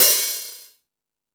Metal Drums(16).wav